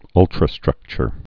(ŭltrə-strŭkchər)